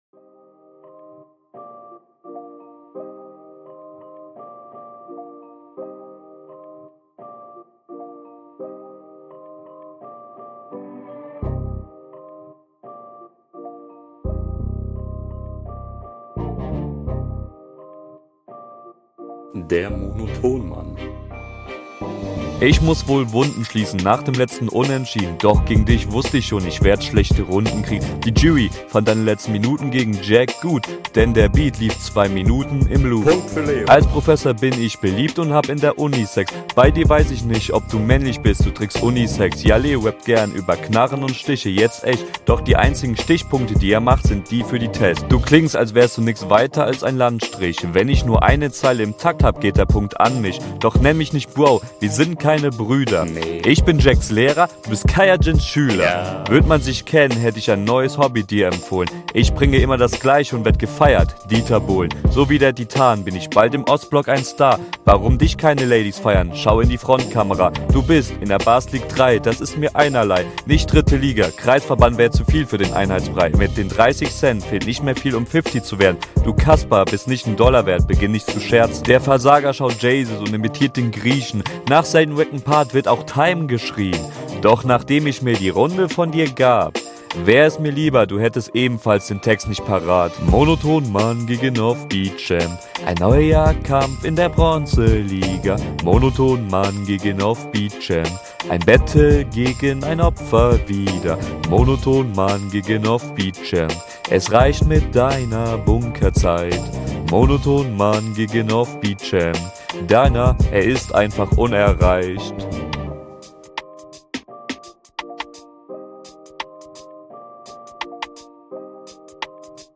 Leider nicht hörbar... Das Mix-Master ist fürchterlich, aber die meisten lines hast du (zum glück) …